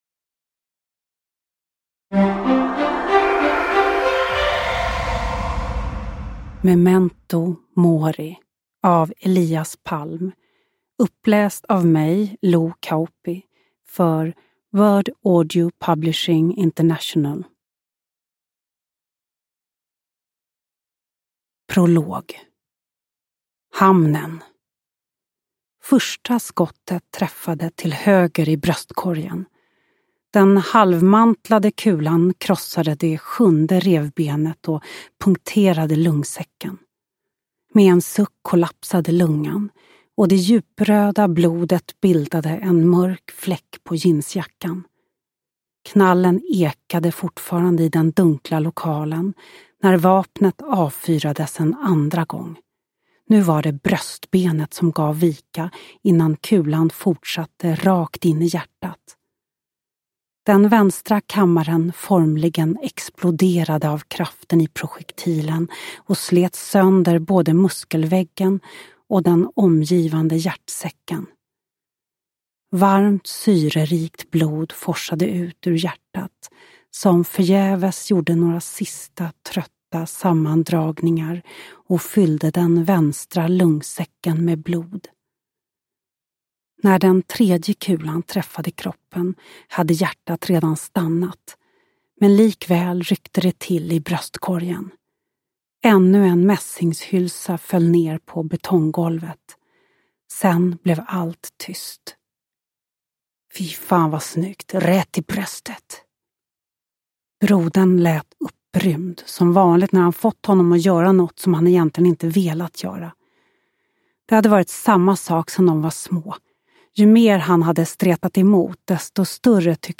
Memento mori (ljudbok) av Elias Palm